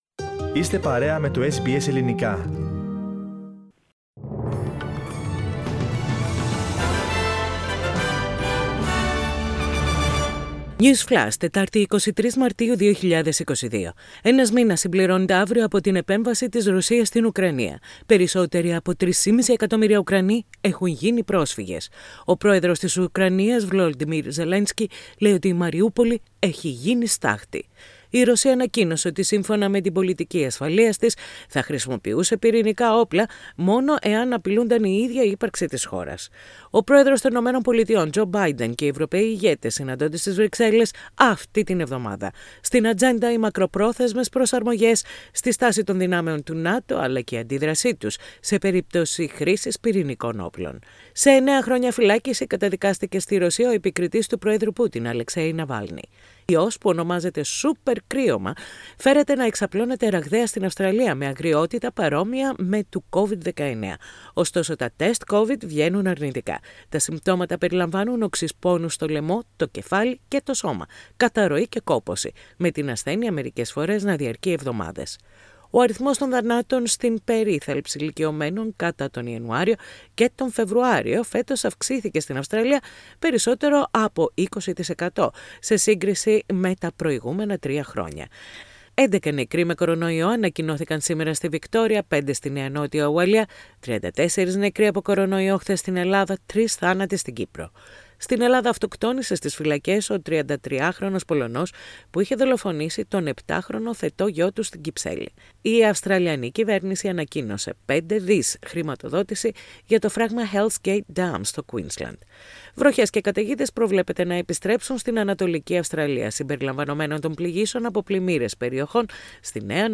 News Flash - Σύντομο δελτίο ειδήσεων - Τετάρτη 23.3.22